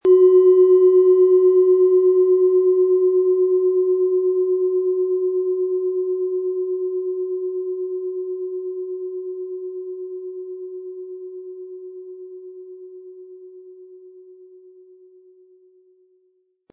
Planetenton 1
Von Hand getriebene tibetanische Planetenschale Jupiter.
Wie klingt diese tibetische Klangschale mit dem Planetenton Jupiter?
MaterialBronze